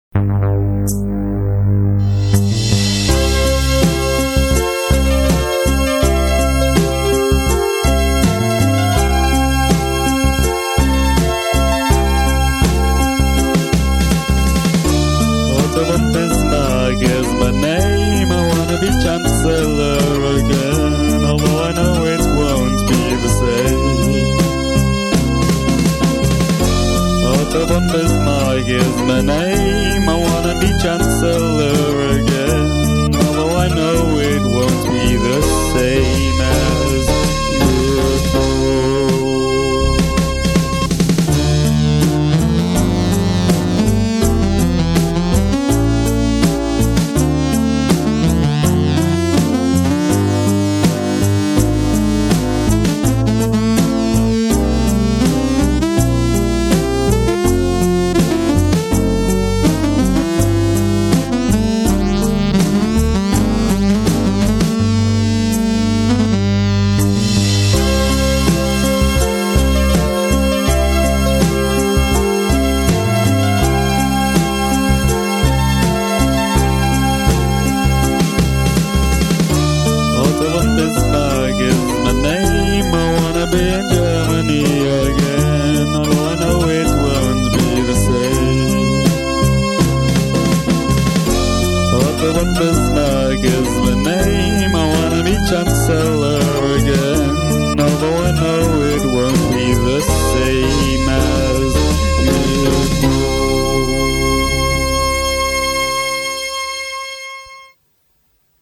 In a way, this song always makes me feel a little sad.